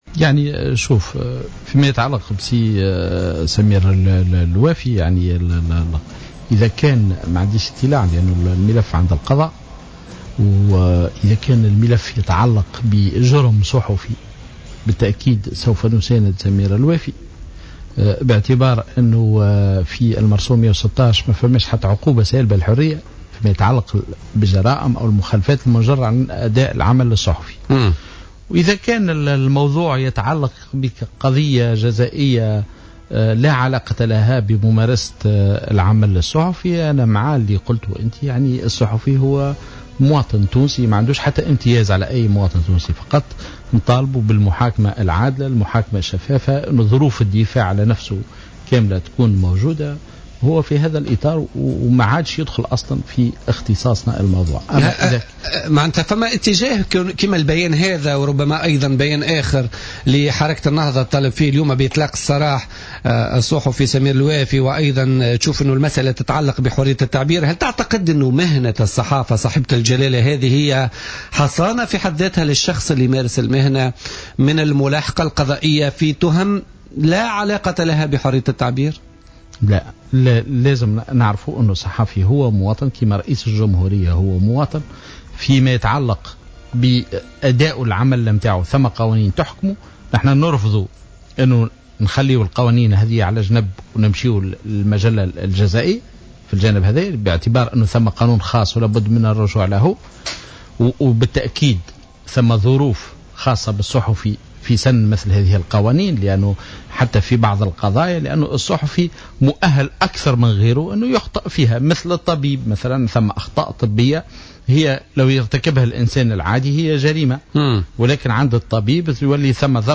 قال عضو الهيئة العليا المستقلة للاتصال السمعي البصري، هشام السنوسي ضيف بوليتيكا على الجوهرة أف أم اليوم الخميس إن "الهايكا" ستساند الإعلامي سمير الوافي في حال تعلقت قضيته بجرم صحفي، ولكن لا يمكن مساندته في حال تعلق الامر بقضية جزائية لا علاقة لها بممارسة العمل الصحفي حيث تخرج القضية عن اختصاص الهيئة.